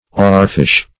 Oarfish \Oar"fish`\ ([=o]r"f[i^]sh`), n. (Zool.)